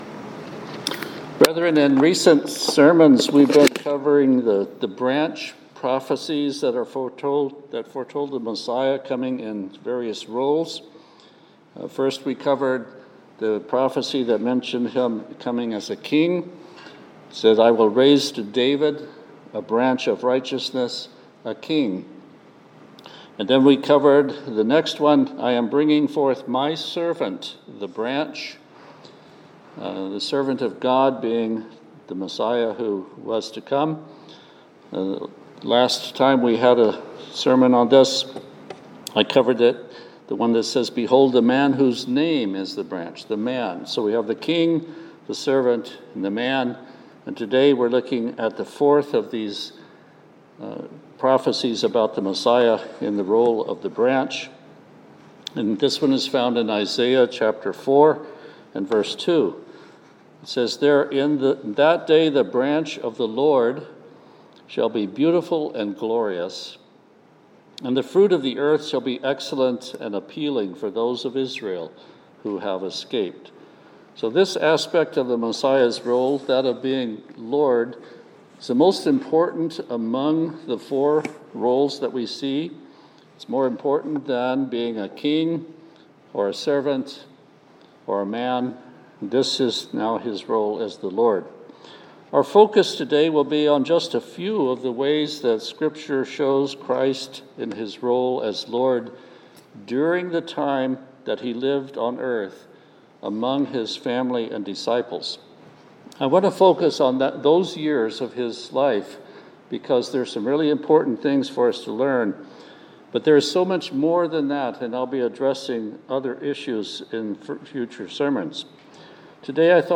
In this split-sermon we consider this fourth aspect of the "Branch" prophecies--King, Servant, Man, and now Lord. We'll focus today on evidence in Scripture of Christ in the role of Lord while He lived on the earth among His family and disciples.
Given in Olympia, WA Tacoma, WA